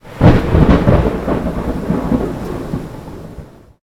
Thunder_1.ogg